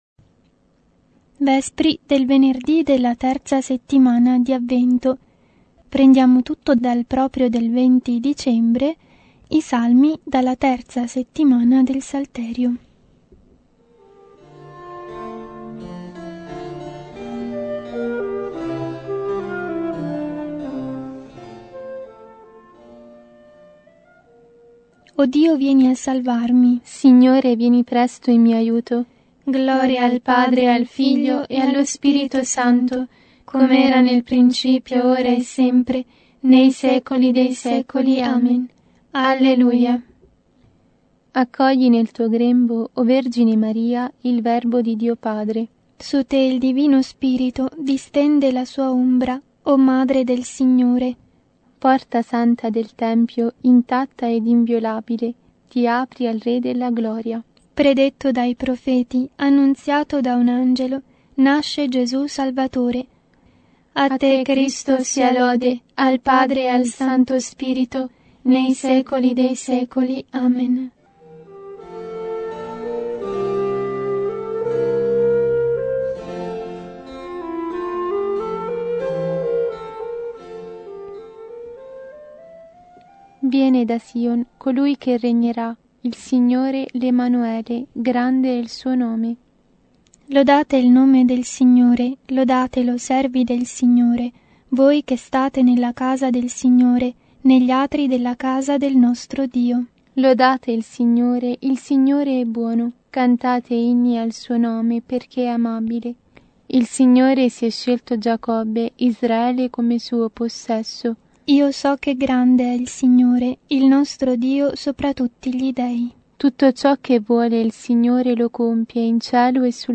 Vespri – 20 dicembre